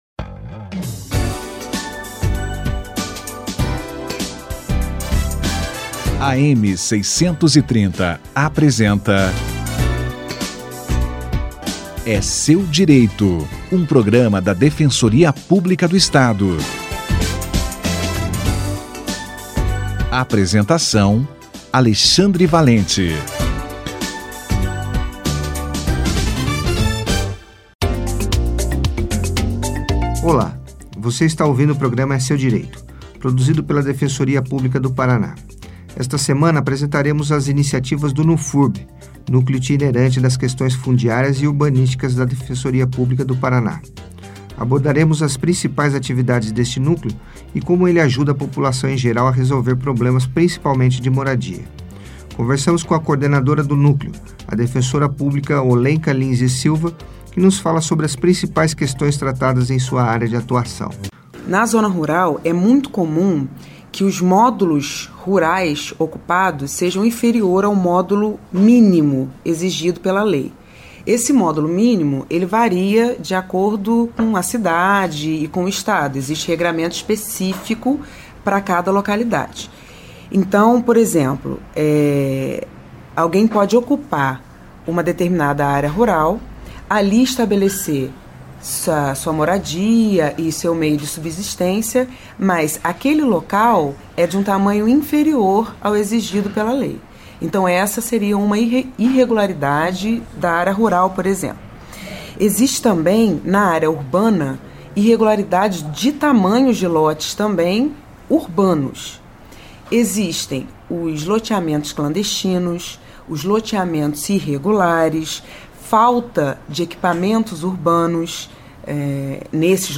As principais atividades do NUFURB entrevista